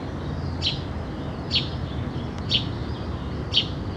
birds02.wav